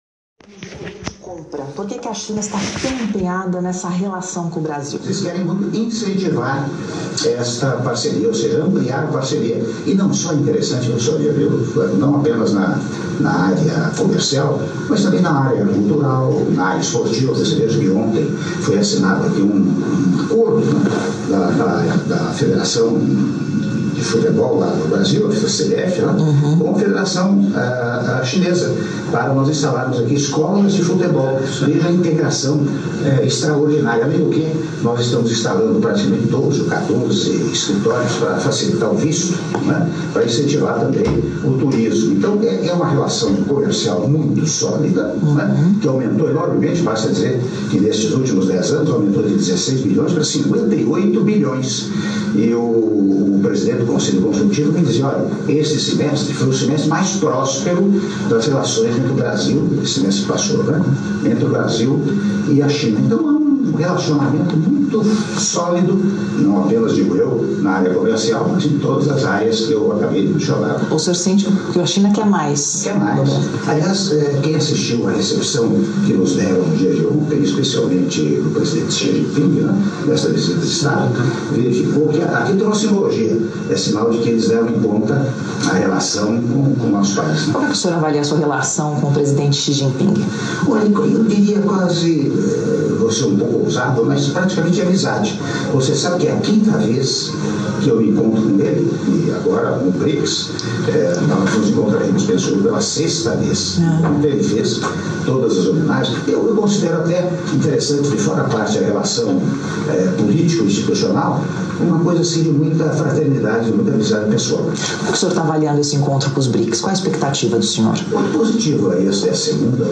Áudio da entrevista exclusiva concedida pelo Presidente da República, Michel Temer - à Band TV - (06min17s) - Pequim/China